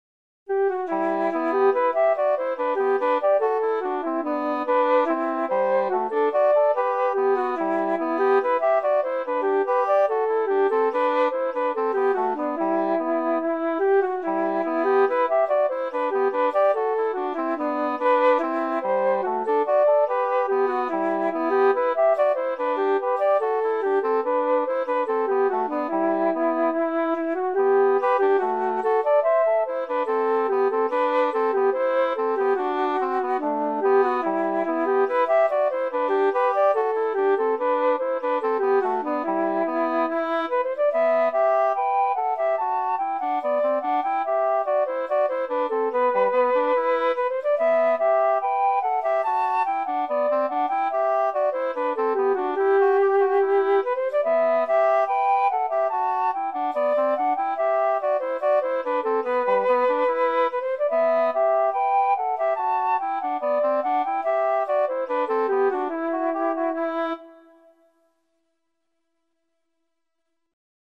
Once again I'm letting a computer-generated duo of flute and English horn play the tune.